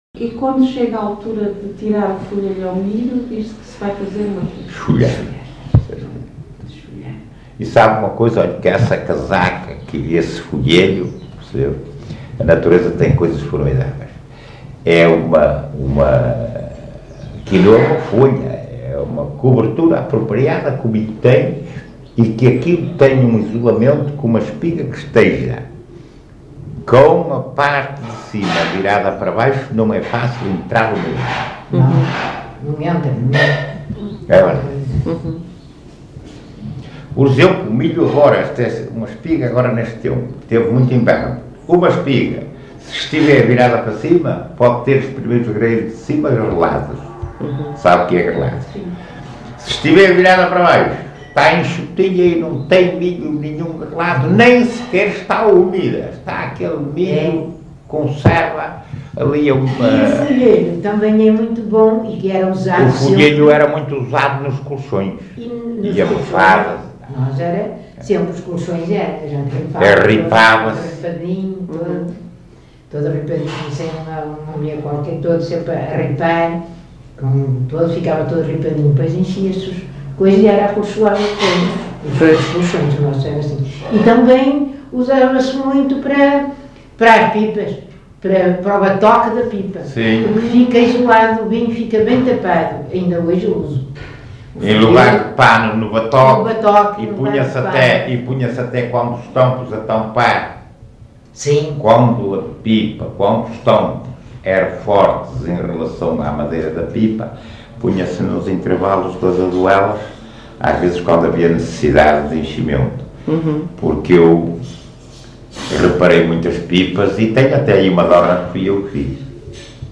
LocalidadeGião (Vila do Conde, Porto)